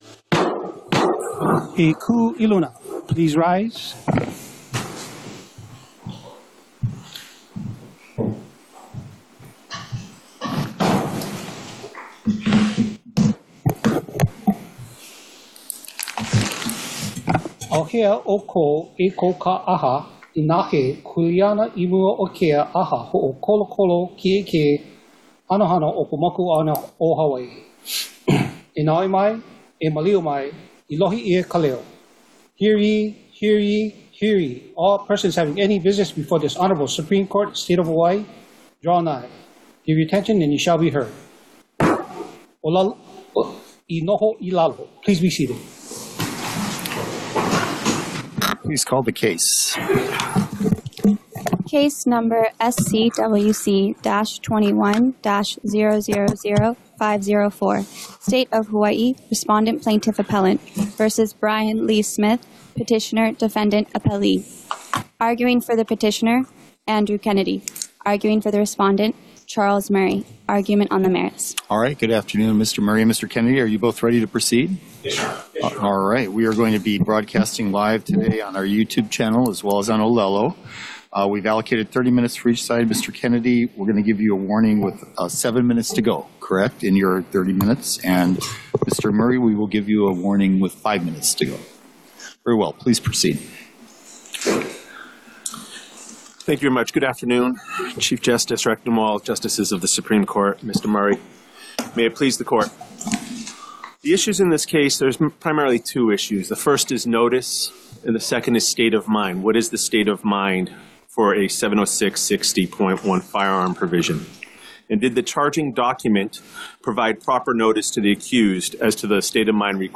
The above-captioned case has been set for oral argument on the merits at: Supreme Court Courtroom Ali‘iōlani Hale, 2nd Floor 417 South King Street Honolulu, HI 96813